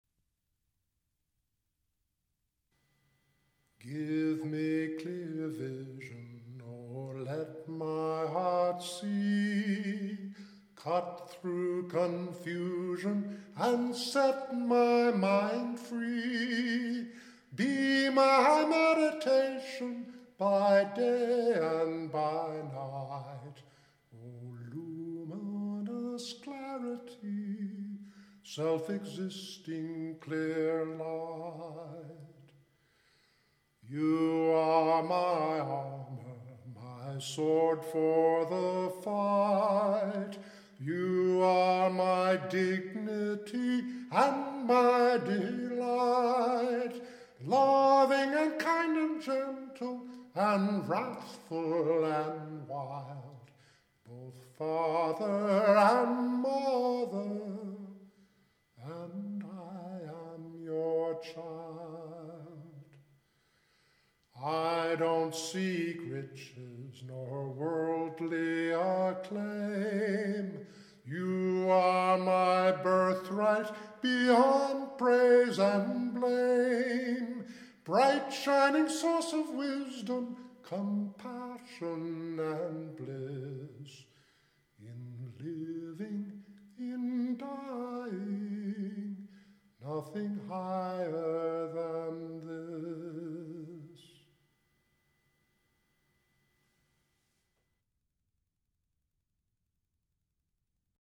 Traditional Irish ballad tune